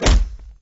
kick1.wav